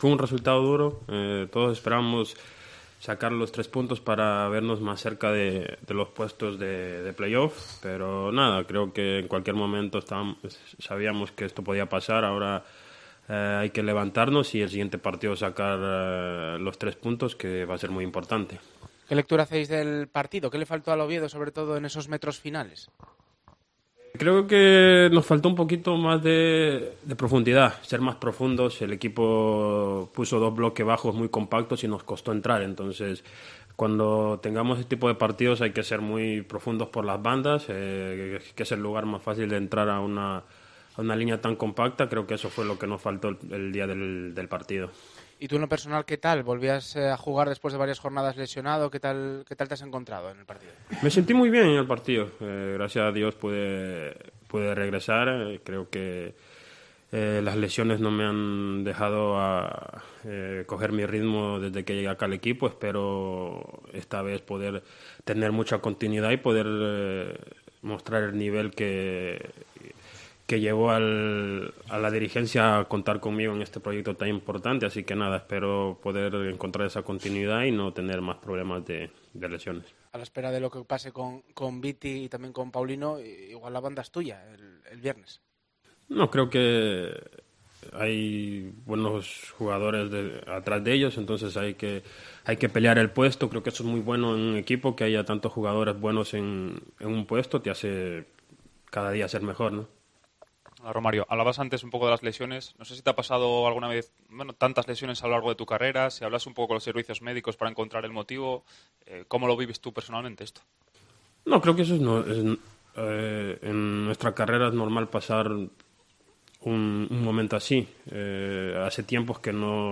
Rueda de prensa de Romario Ibarra, jugador del Real Oviedo